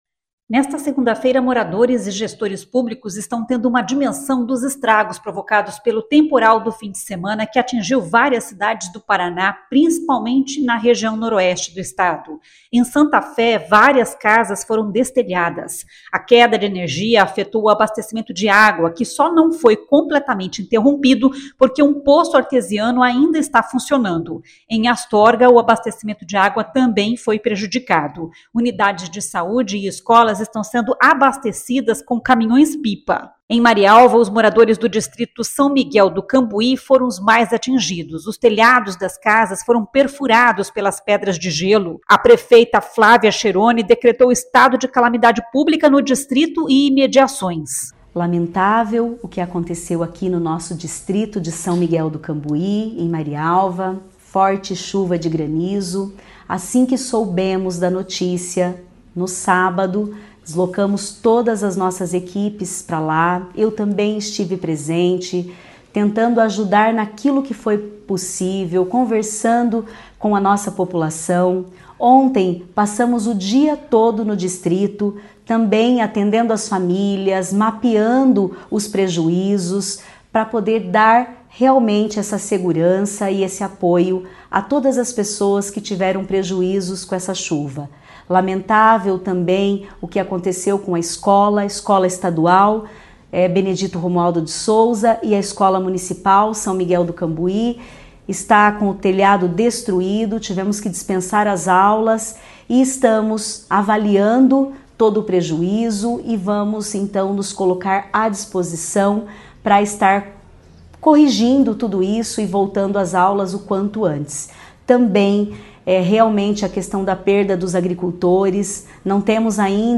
A prefeita Flávia Cheroni decretou estado de calamidade pública no distrito e imediações. [ouça o áudio]
Nesta segunda-feira (3) não teve aula e nem atendimento nas UBSs, diz o prefeito Carlos Caxão. [ouça o áudio]